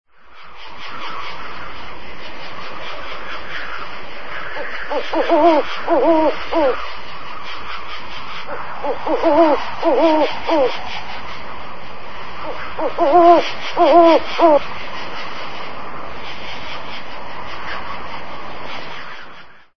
BOSQUE BUHOS
Tonos EFECTO DE SONIDO DE AMBIENTE de BOSQUE BUHOS
bosque_buhos.mp3